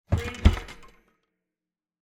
Cabinet Door Close Wav Sound Effect #14
Description: The sound of a wooden cabinet door being closed (and some loose items attached to the door rattling)
Properties: 48.000 kHz 16-bit Stereo
Keywords: cabinet, door, close, closing, shut, shutting
cabinet-door-close-preview-14.mp3